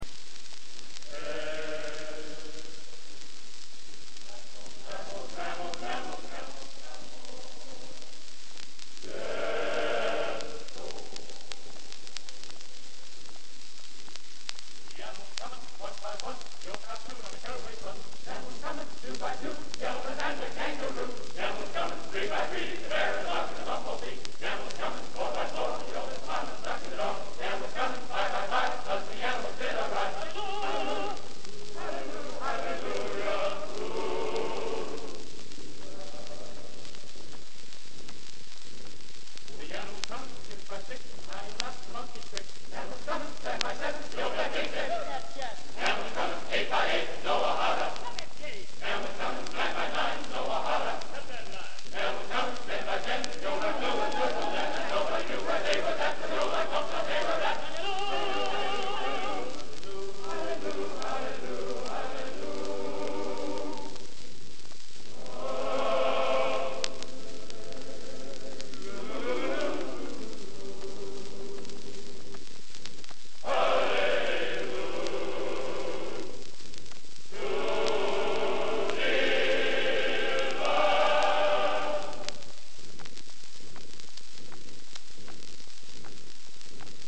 Genre: Spiritual | Type: Studio Recording